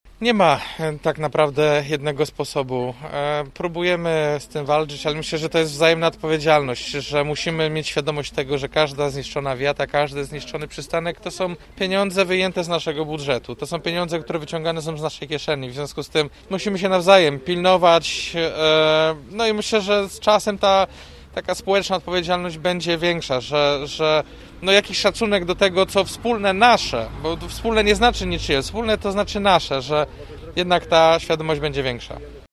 – Za naprawę tej wiaty zapłacą wszyscy gorzowianie – mówi prezydent Jacek Wójcicki: